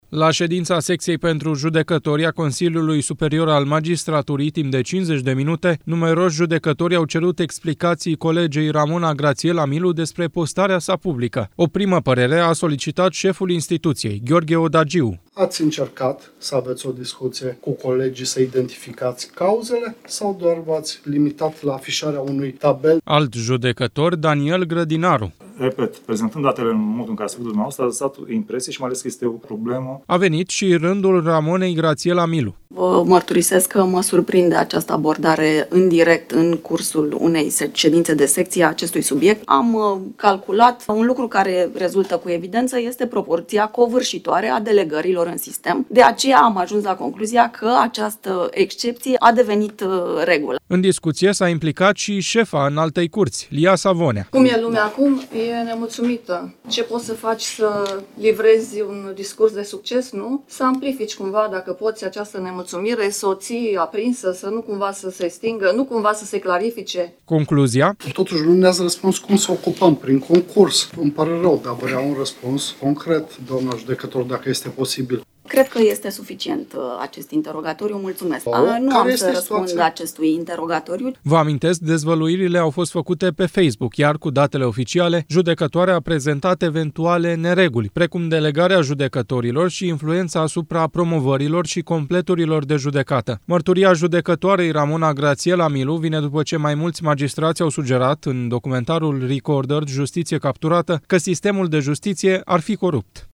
La ședința Secției pentru Judecători a Consiliului Superior al Magistraturii, timp de 50 de minute, numeroși judecători au cerut explicații colegei Ramona Grațiela Milu despre postarea sa publică.